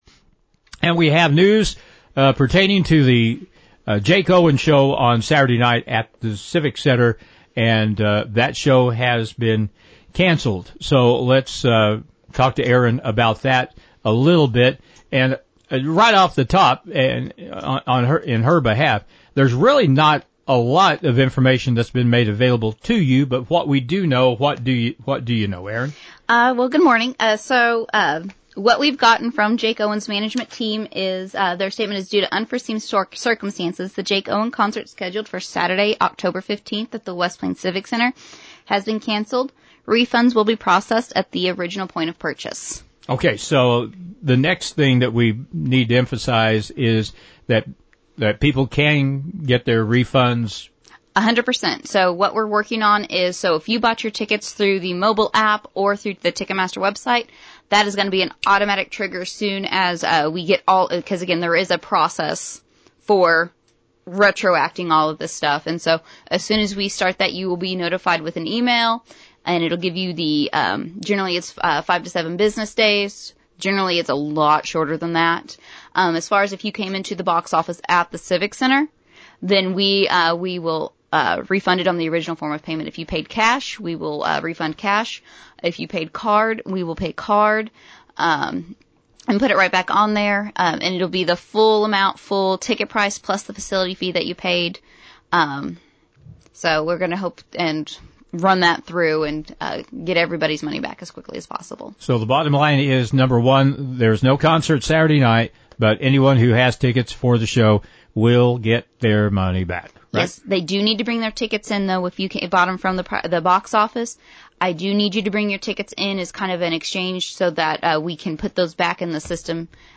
on 102.5 KKDY Wednesday morning about the cancellation: